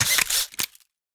internal_modification.ogg